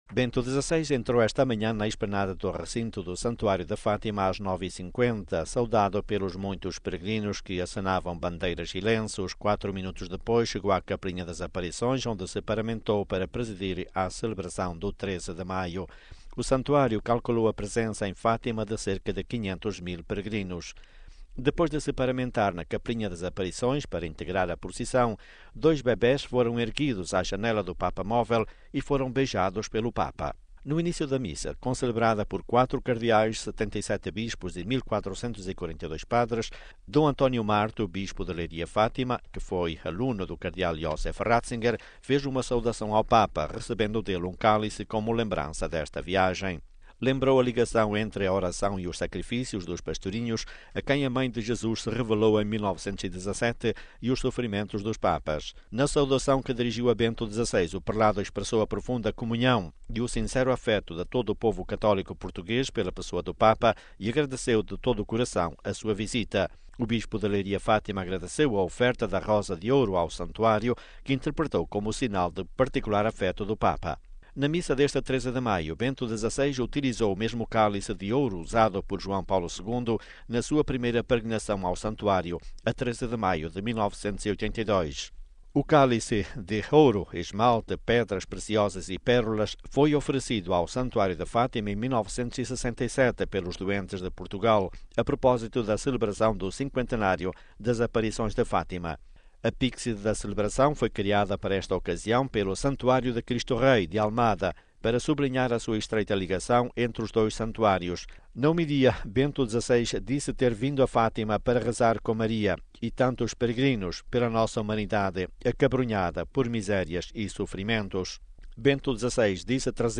De Fátima